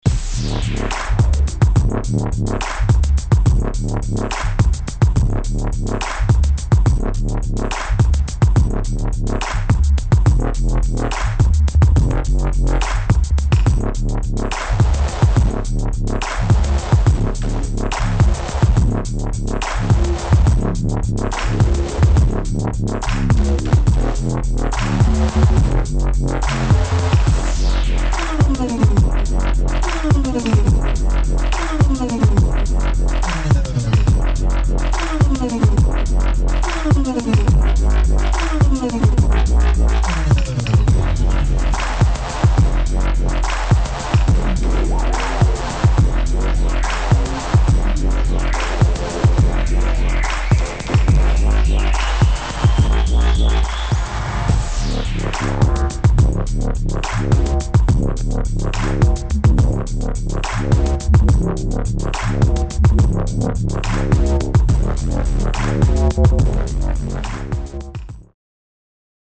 [ BASS | UK GARAGE ]